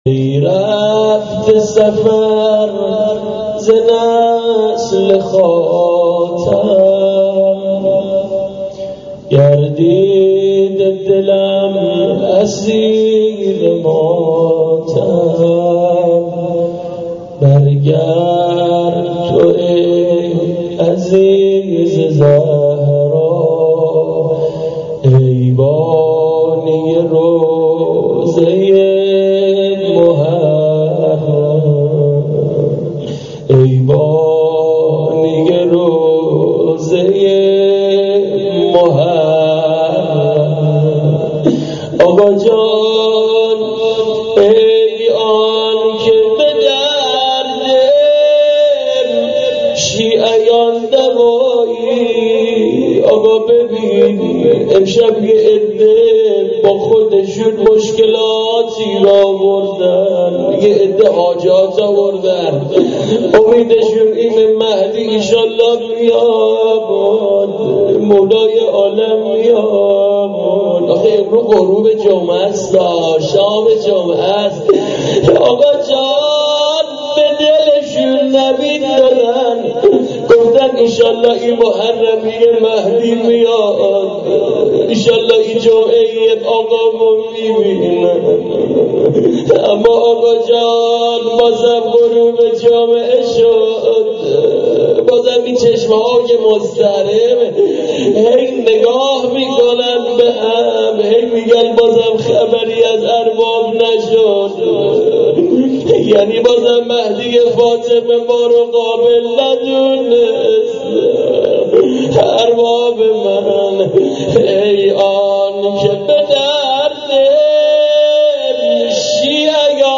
مناجات با امام زمان روضه حضرت زینب.MP3